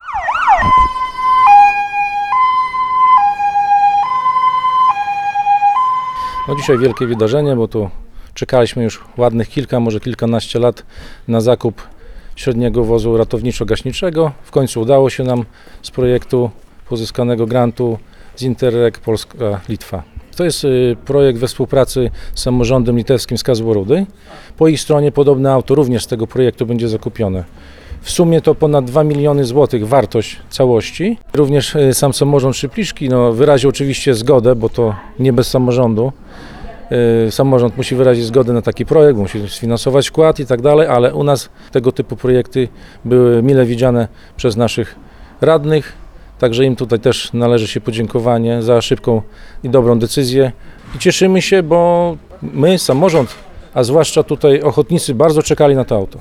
W sobotę (19.10) w remizie OSP w Kaletniku, odbył się uroczysty odbiór nowego pojazdu.
– Nowy wóz to efekt porozumienia władz samorządu po stronie polskiej i litewskiej – mówi Radiu 5 Mariusz Grygieńć, wójt gminy Szypliszki.